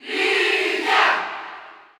Crowd cheers (SSBU) You cannot overwrite this file.
Lucas_Cheer_French_PAL_SSBU.ogg